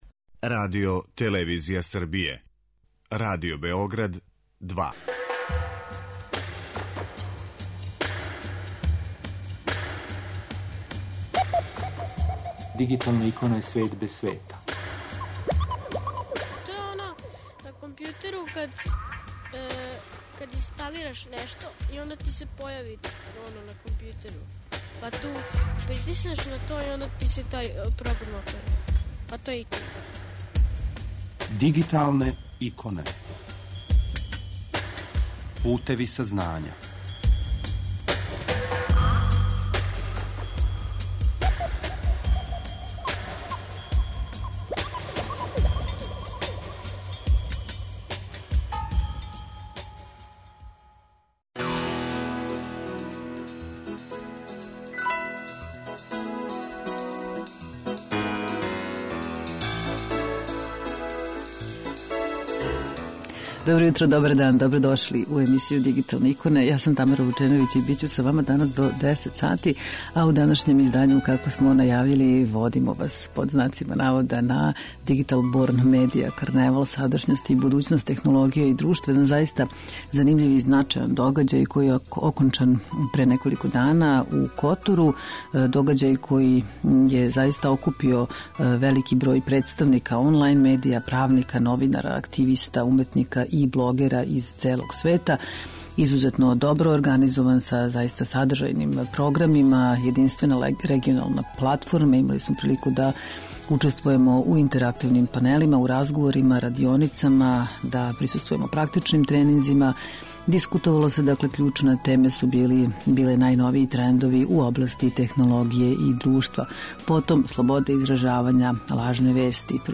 У данашњој емисији Дигиталне иконе, слушамо део разговора снимљених у току трајања овог јединственог догађаја.